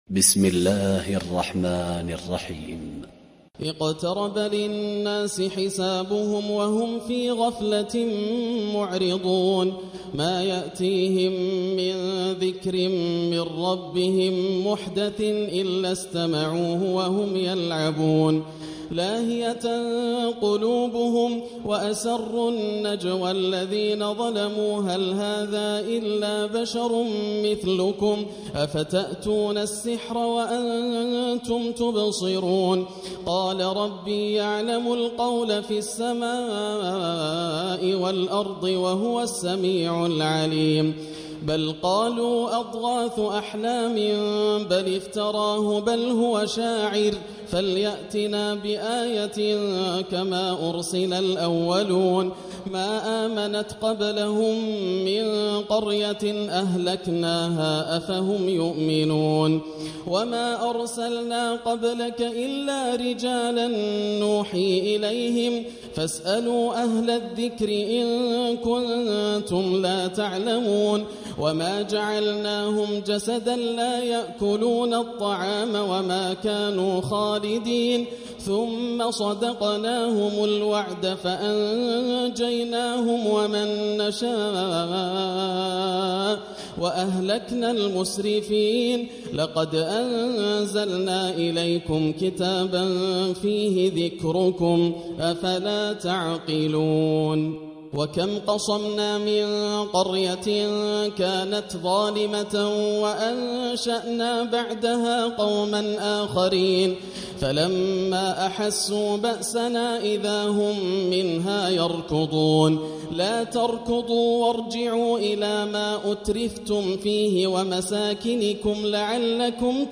سورة الأنبياء من تراويح رمضان 1440 هـ > السور المكتملة > رمضان 1440هـ > التراويح - تلاوات ياسر الدوسري